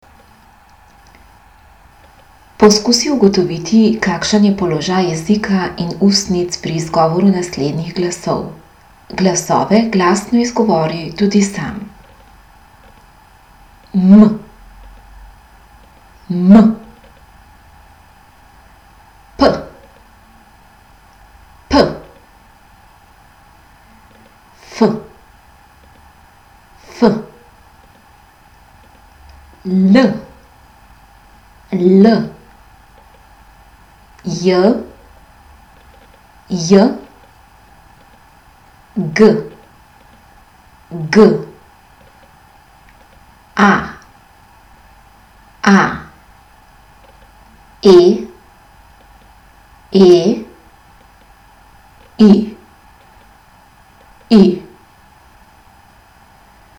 Poslušaj nekaj glasov in jih glasno ponovi.
Pravkar slišane glasove lahko poimenujemo takole:  m – nosni ustničnoustnični, p – ustničnoustnični, f – zobnoustnični, l – zobnojezični, j – nebnojezični, g – mehkonebnojezični.